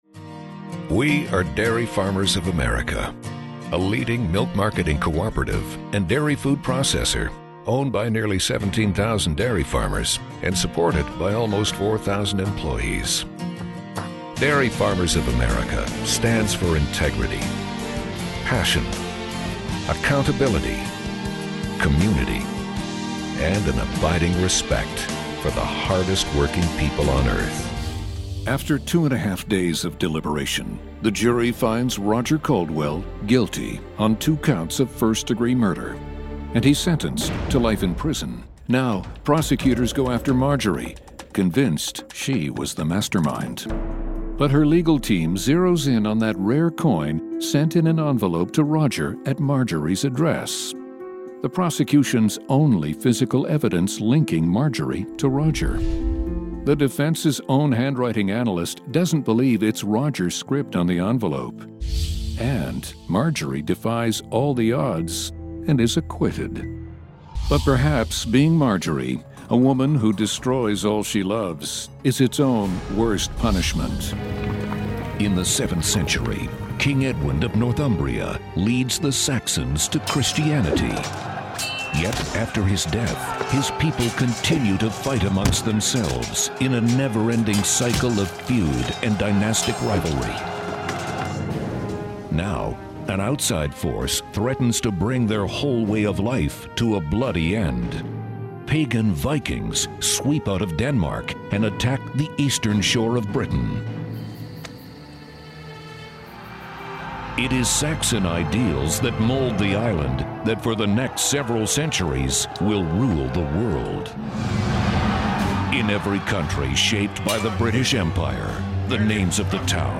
Male VOs
Listen/Download – Narration